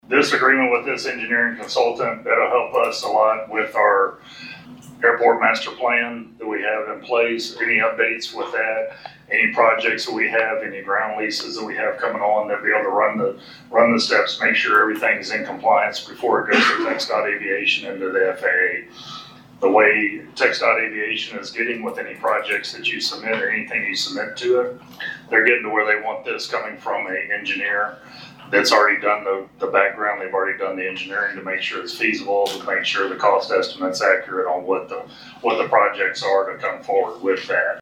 informed councilmembers at the Huntsville City Councils regular session this week